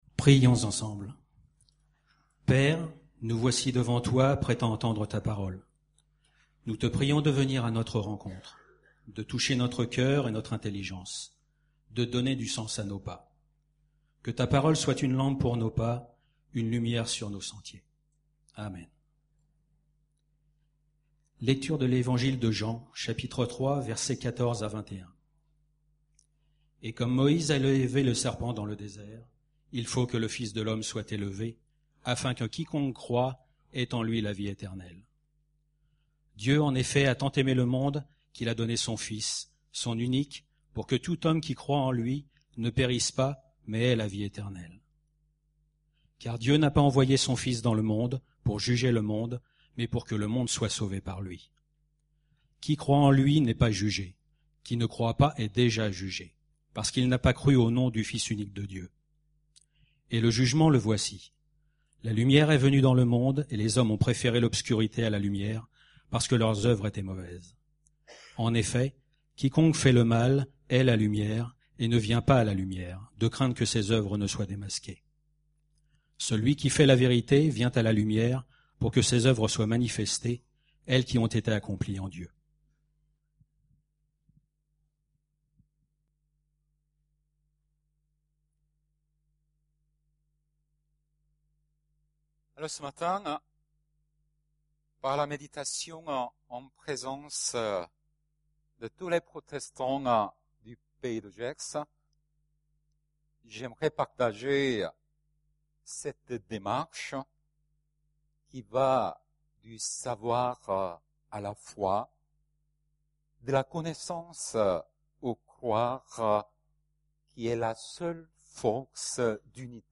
Culte en commun du 15 mars